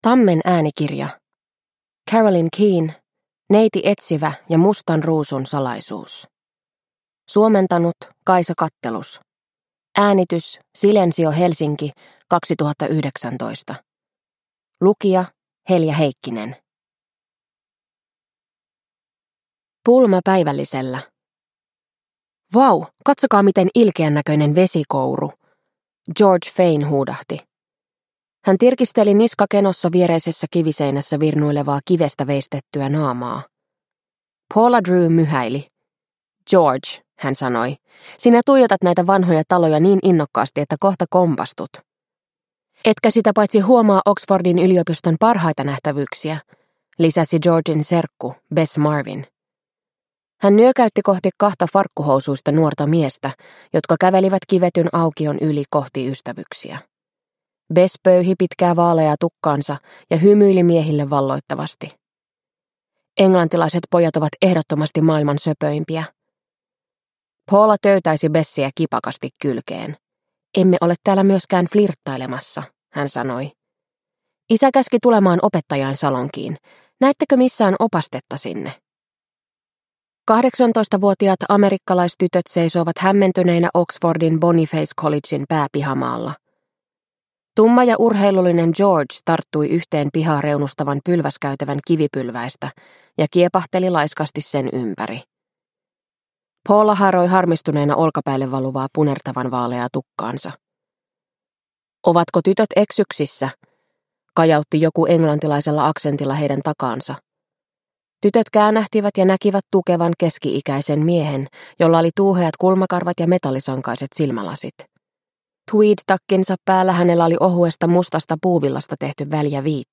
Neiti Etsivä ja mustan ruusun salaisuus – Ljudbok – Laddas ner